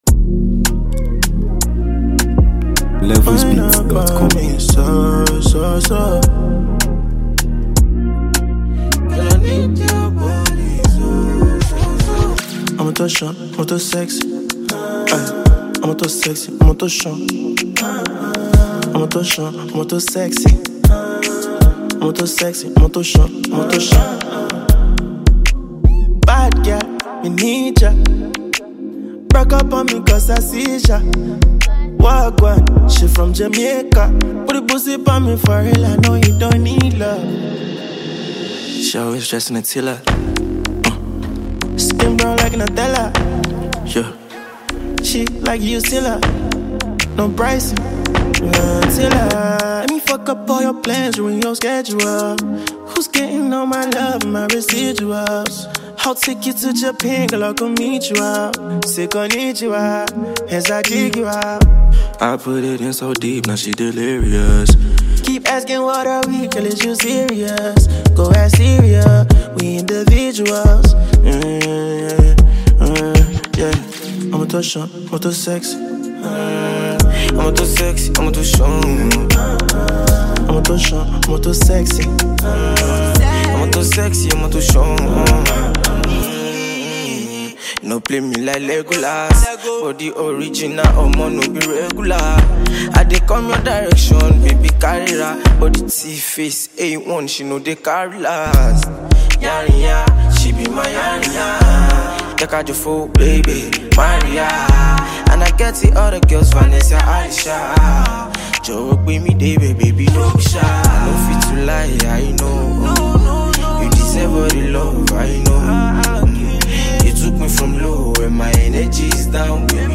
adding layers of rhythm and vibe that make it irresistible.
For fans of Afrobeat